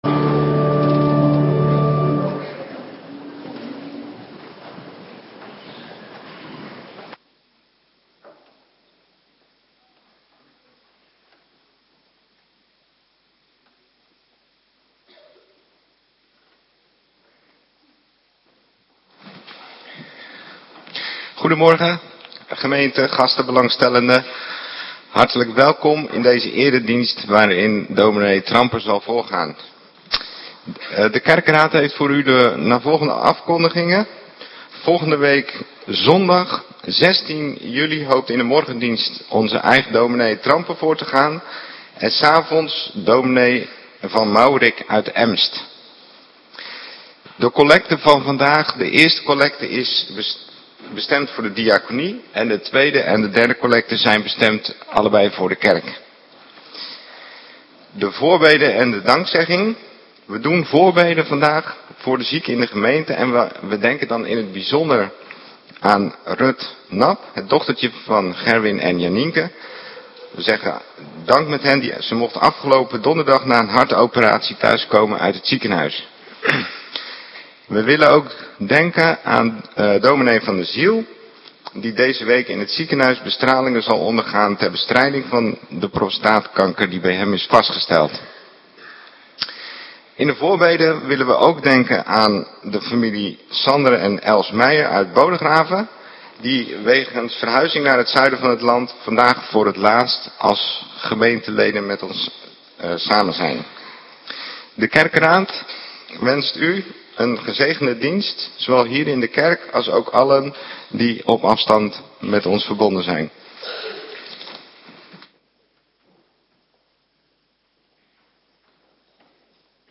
Ps 100 . 1 en 4 Ps 119 . 70 Hand 16 . 22/34 Formulier Ps 105 . 5 Ps134 . 3 Prediking Ps . 128 . 1 . 2 en 4 Ps 47 . 4 Ps . 2 Thema:U en uw huisgezin